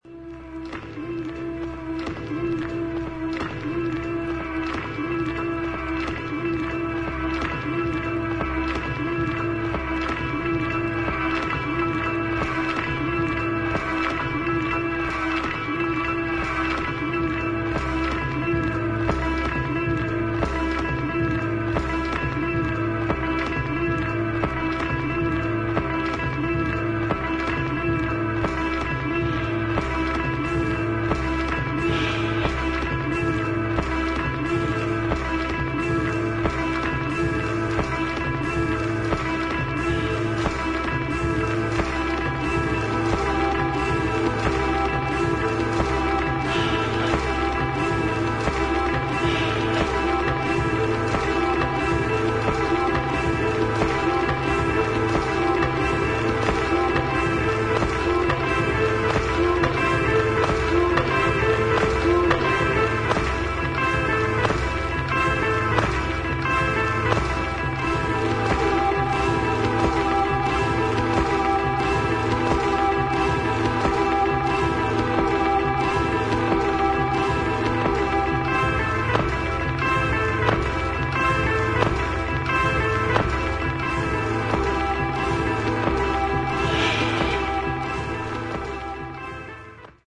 80'sエレクトロニックな感覚とシネマティックな雰囲気が随所に感じられるコンセプチュアルなコンピレーション作品